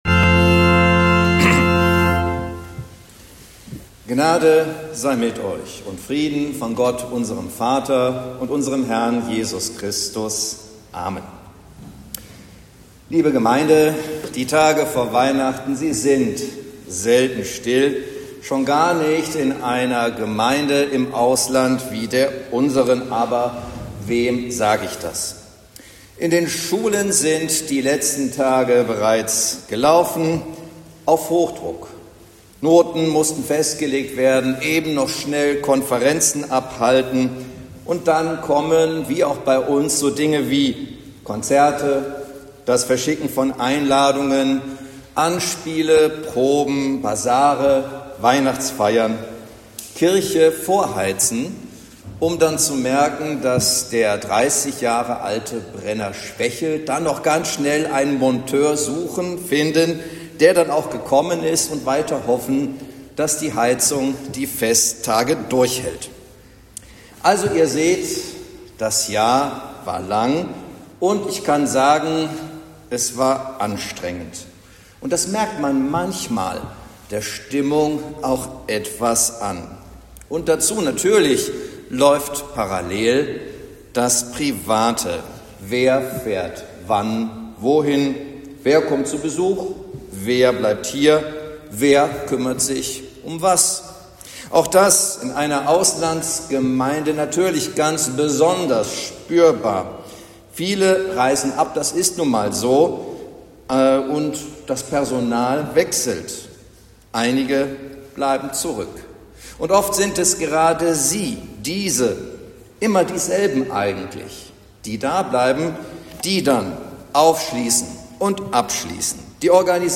Predigt zum 4. Advent